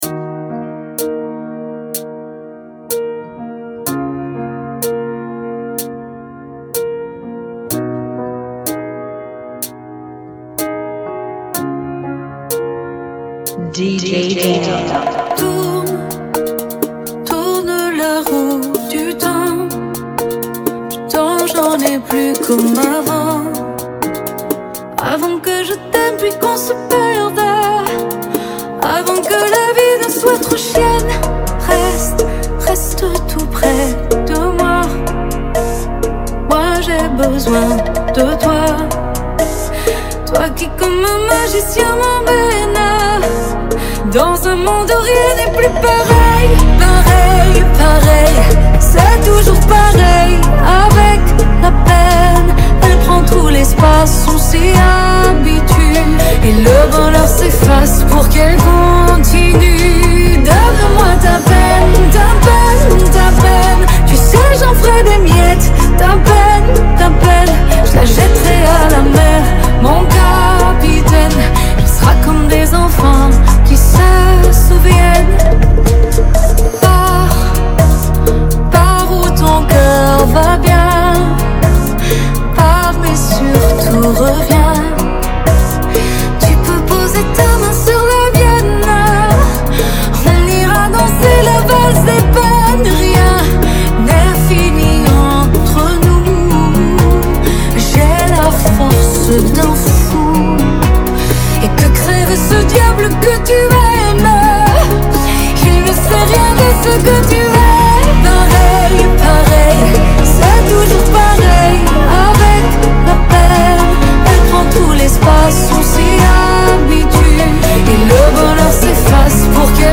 Bachata Remix